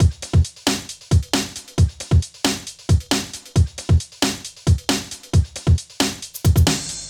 KIN Beat - Full 2.wav